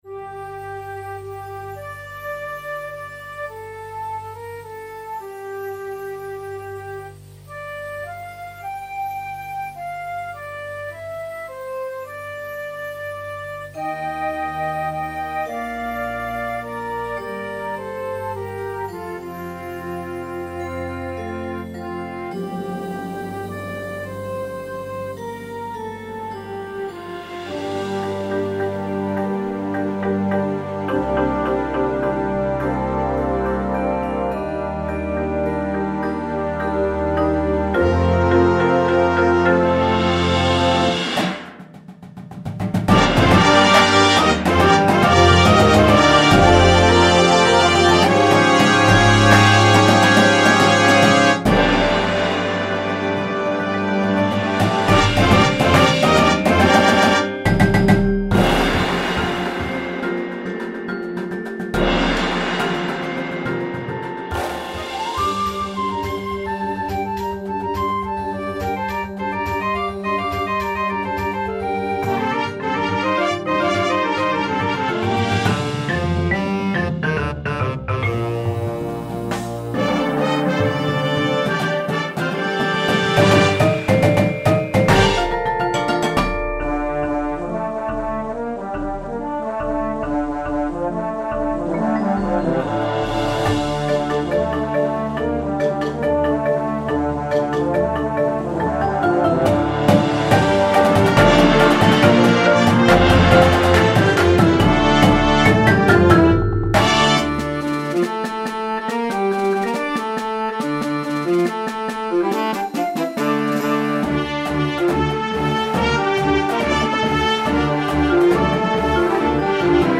• Flute
• Clarinet 1, 2
• Alto Sax 1, 2
• Trumpet 1, 2
• Horn in F
• Tuba
• Snare Drum
• Synthesizer
• Marimba – Two parts
• Vibraphone – Two parts
• Glockenspiel